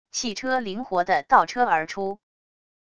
汽车灵活的倒车而出wav音频